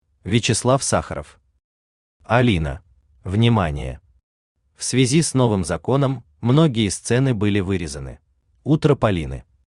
Aудиокнига Алина Автор Вячеслав Валерьевич Сахаров Читает аудиокнигу Авточтец ЛитРес.